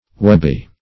Webby \Web"by\, a.